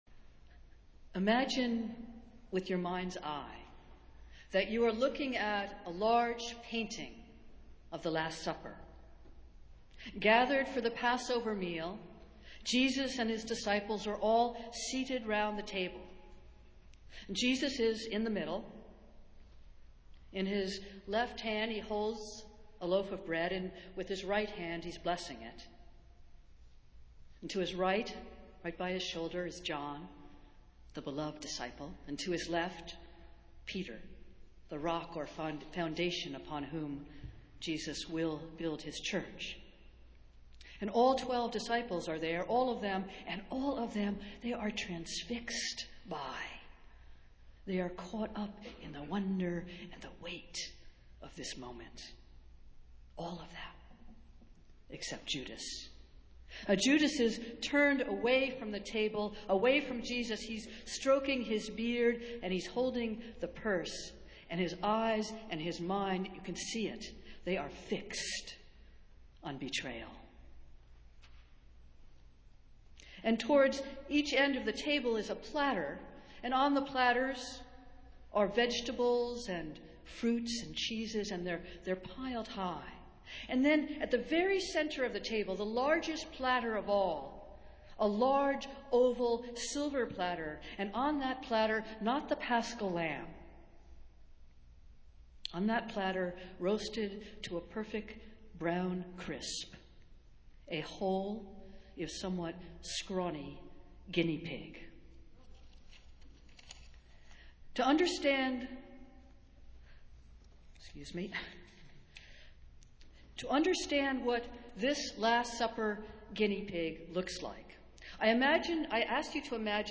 Festival Worship - Fifth Sunday in Lent